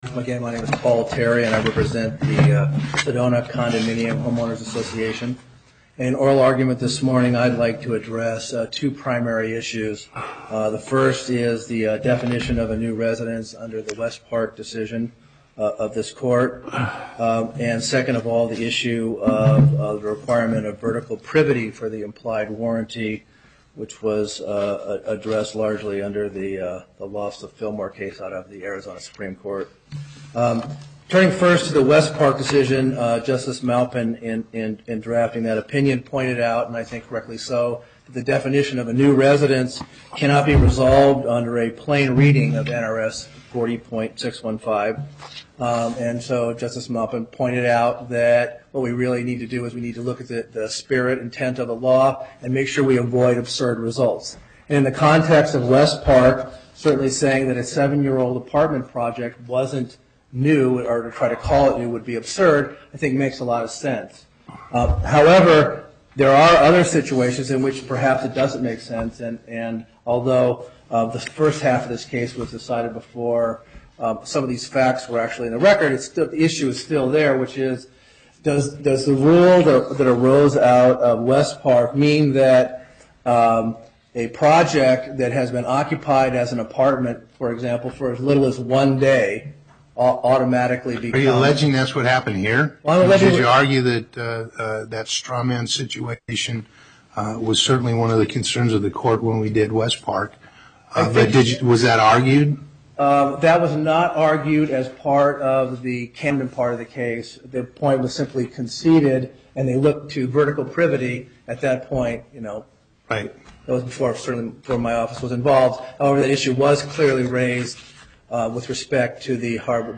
Location: Las Vegas